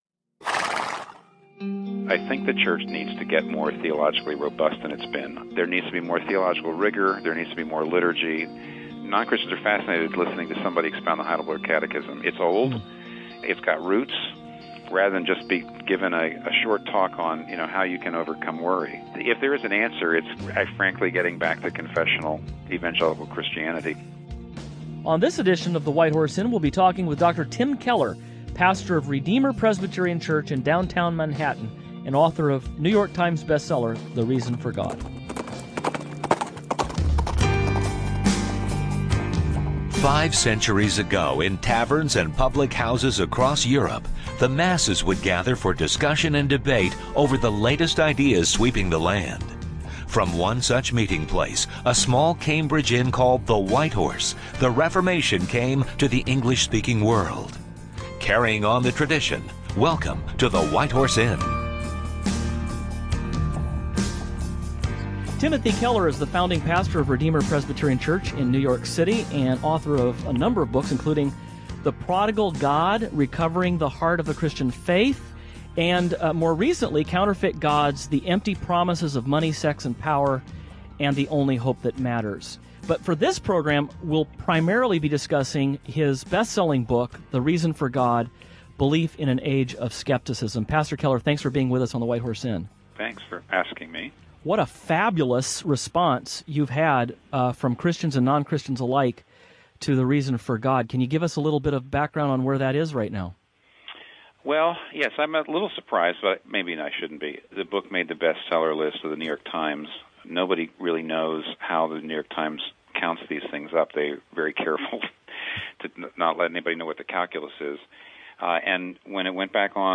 These questions and more will be addressed on this edition of the White Horse Inn as Tim Keller joins the panel…